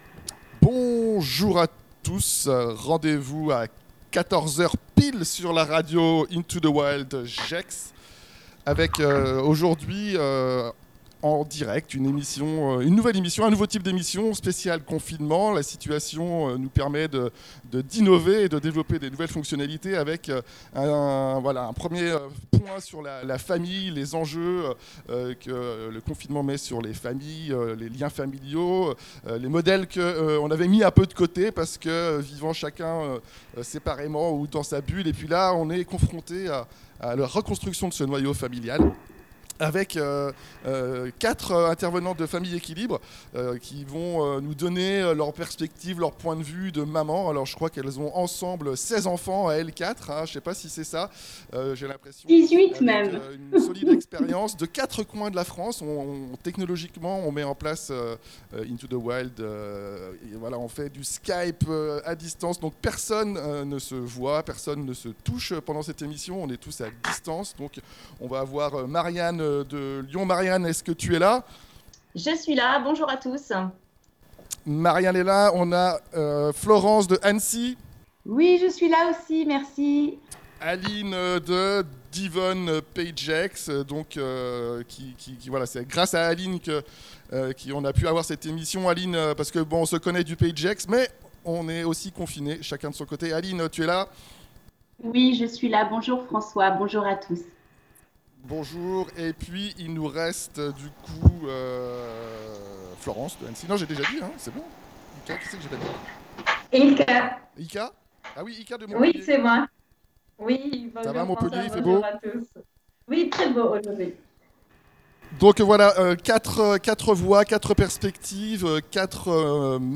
Emission confinement et famille en direct avec les cofondatrices de famille équilibre sur la radio Into The Wild, live simultané depuis 4 coins de France!